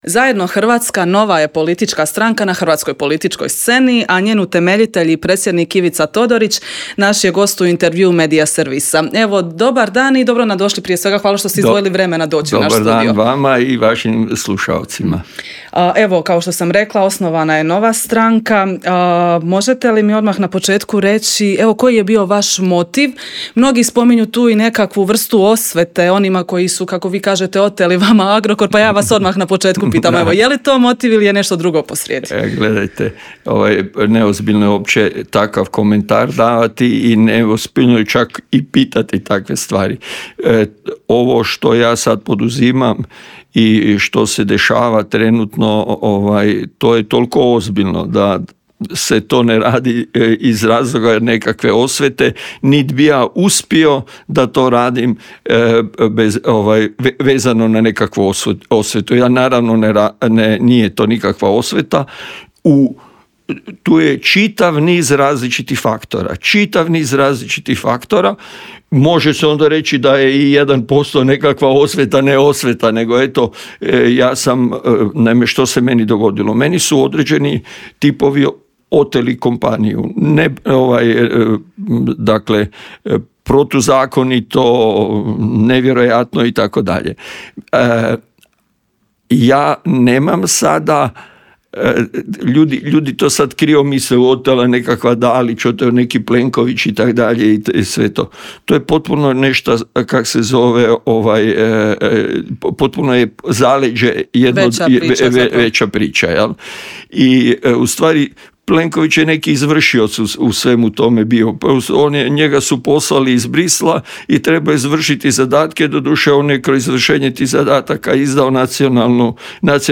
ZAGREB - ‘Zajedno Hrvatska‘ nova je stranka na hrvatskoj političkoj sceni, a njen utemeljitelj i predsjednik Ivica Todorić bio je gost u Intervjuu Med...